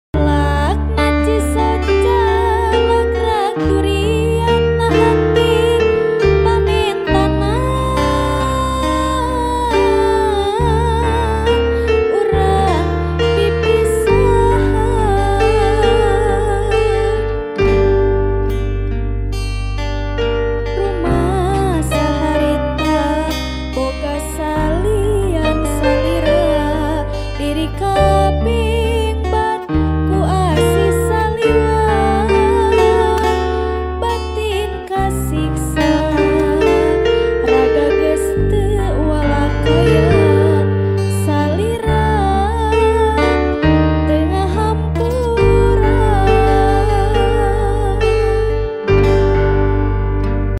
LIVE ACOUSTIC COVER
Gitar
Gitar Bass
Keyboard